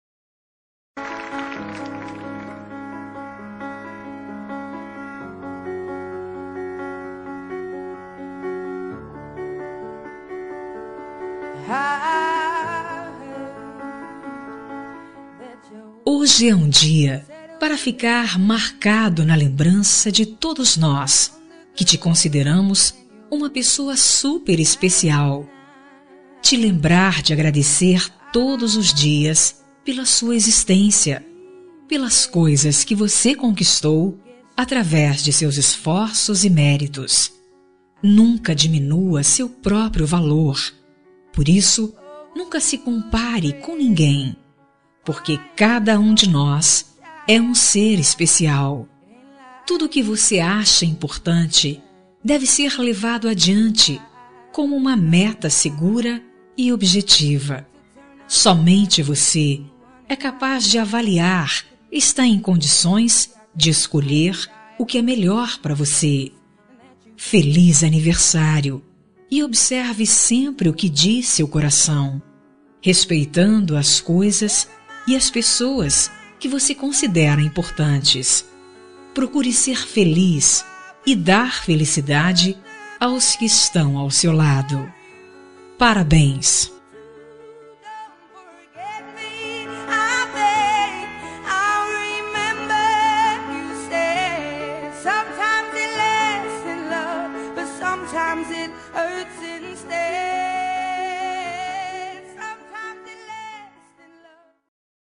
Aniversário de Pessoa Especial – Voz Feminina – Cód: 202055